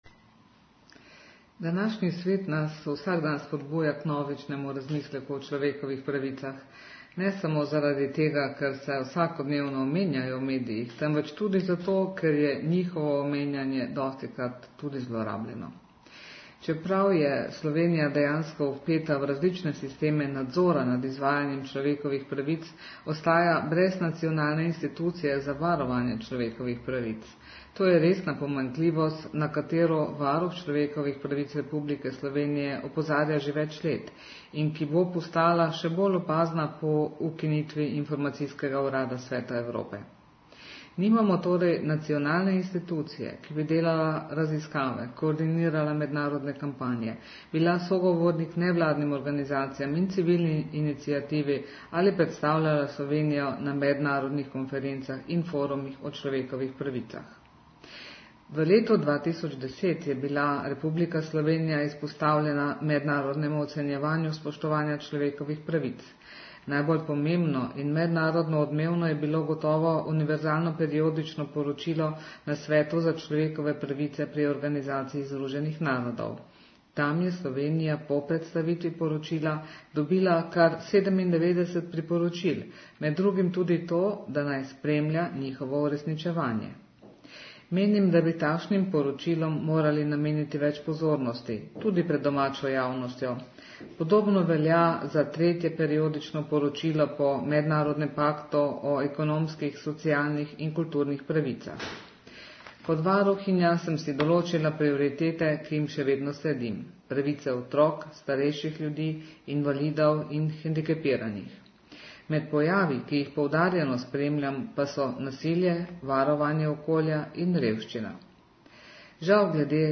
Zvočni posnetek novinarske konference (MP3)